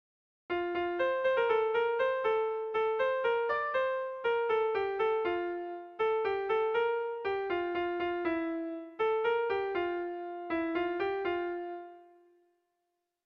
Kontakizunezkoa
10A / 10A / 10B / 8B
ABDE